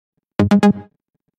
Nada notifikasi iPhone Synth
Kategori: Nada dering
nada-notifikasi-iphone-synth-id-www_tiengdong_com.mp3